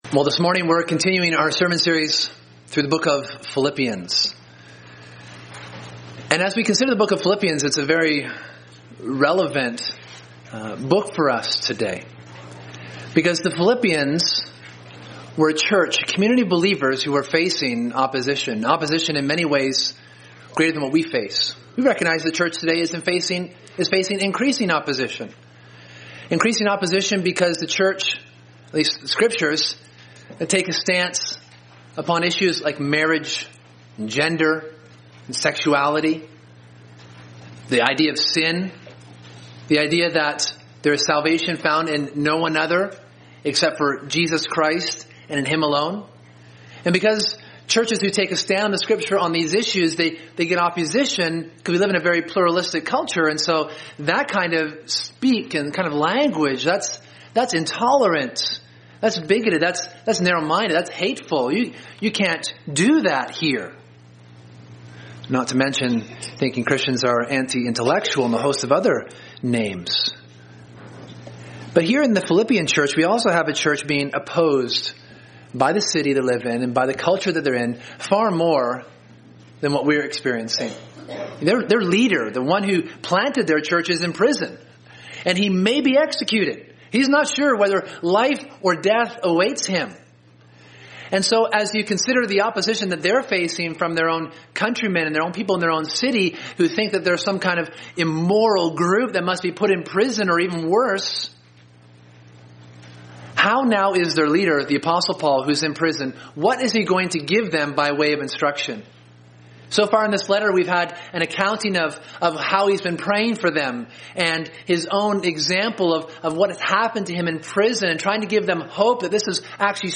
Sermon: Living Worthy of the Gospel